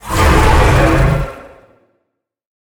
Sfx_creature_hiddencroc_alert_01.ogg